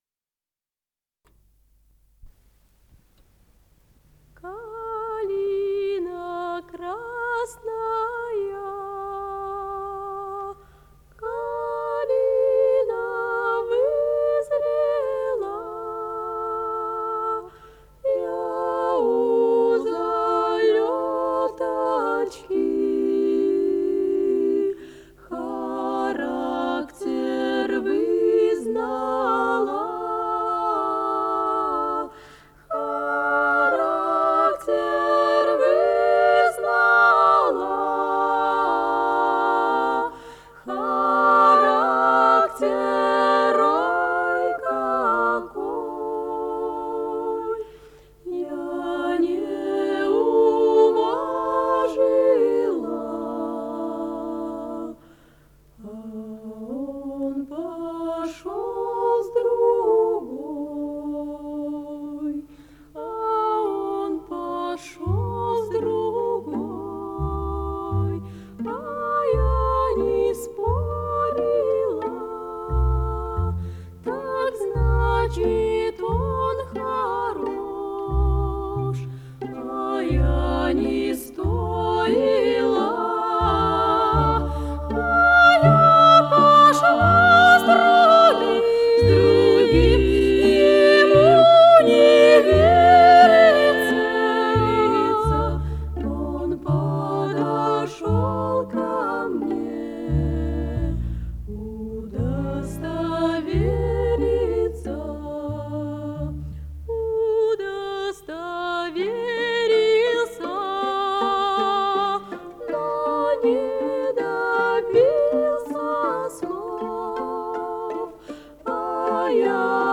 с профессиональной магнитной ленты
АккомпаниментИнструментальный ансамбль
ВариантМоно